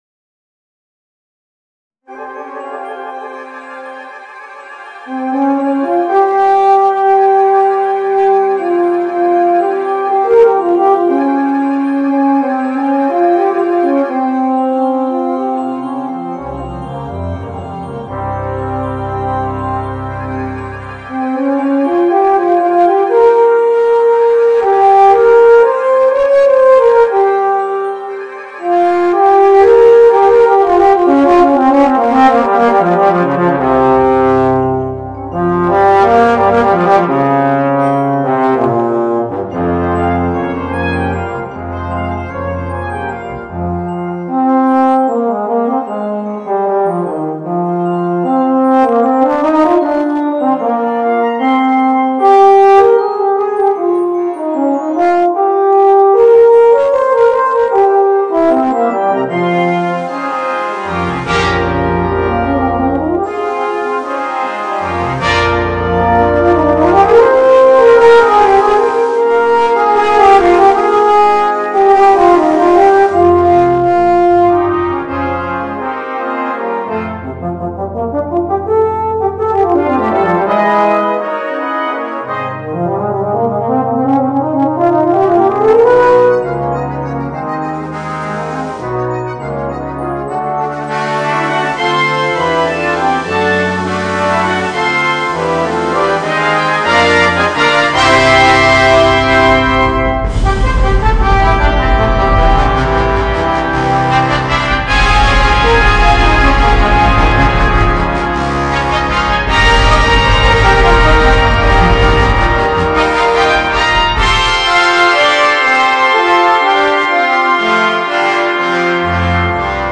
Euphonium and Brass Band